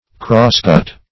Crosscut \Cross"cut`\ (-k[u^]t`), v. t.